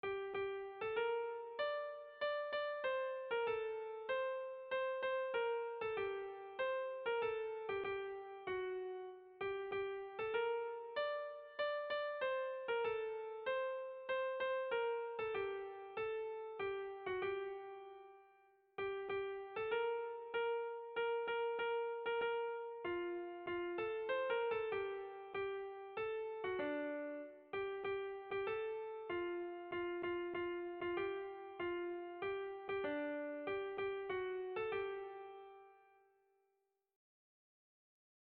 Erlijiozkoa
Seiko handia (hg) / Hiru puntuko handia (ip)
AAABD